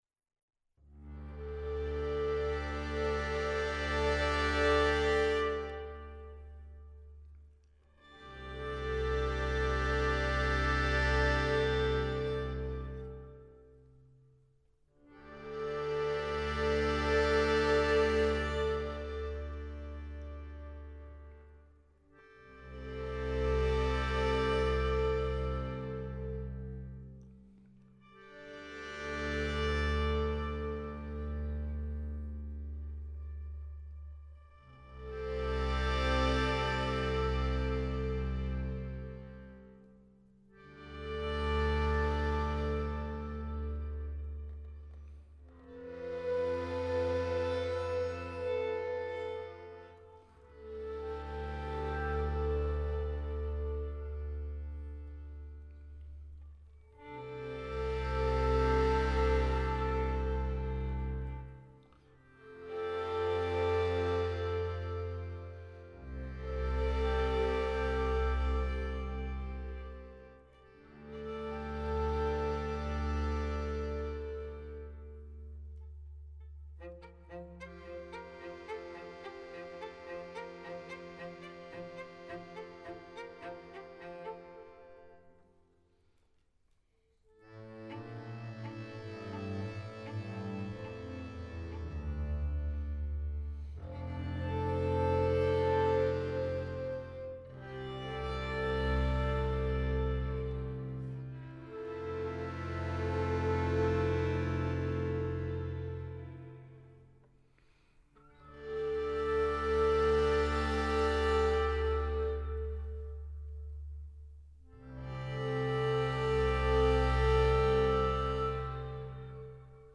Жанр: Alternatif.